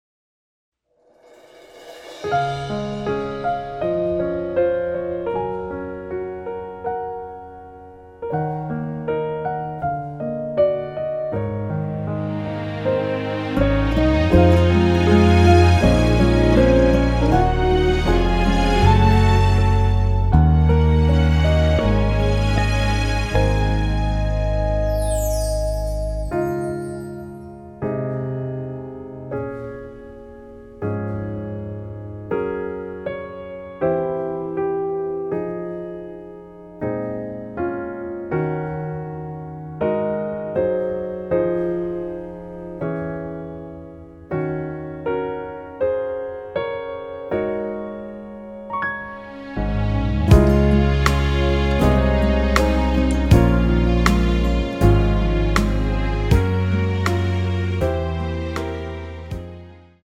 키 Db 가수
원곡의 보컬 목소리를 MR에 약하게 넣어서 제작한 MR이며